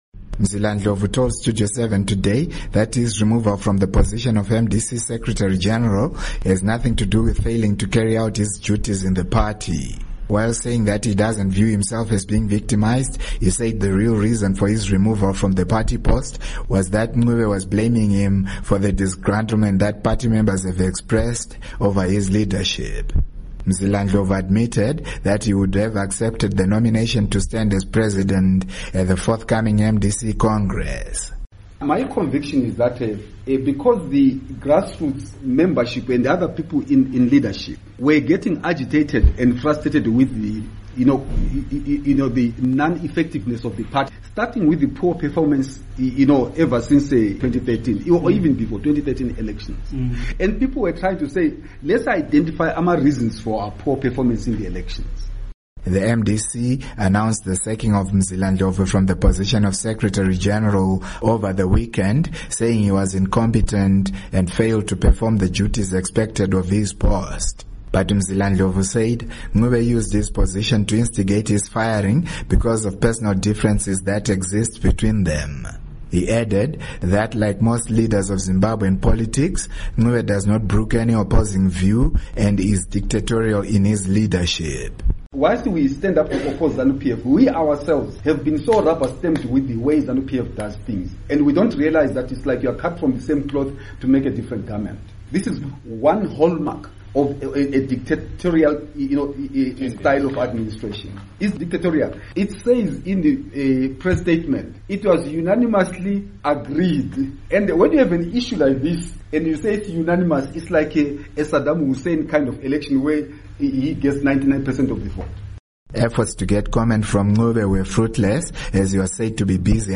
Report on MDC Ncube Squables